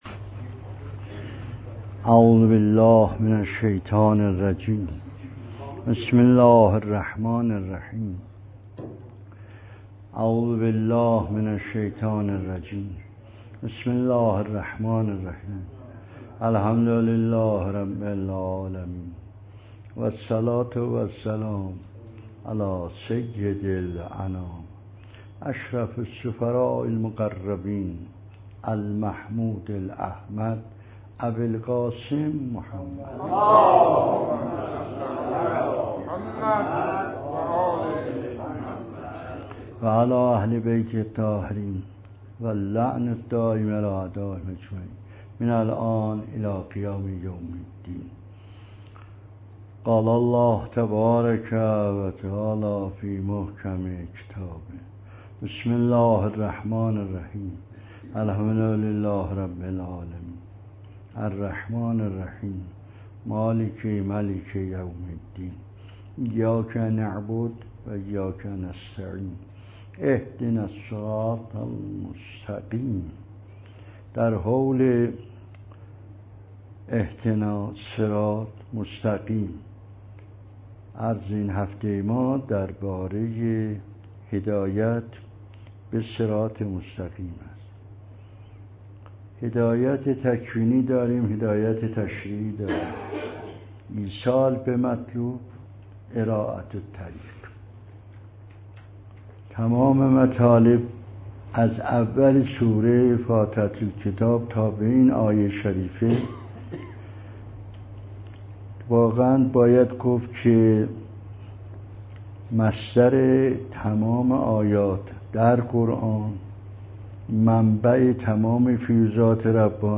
جلسه تفسیر قرآن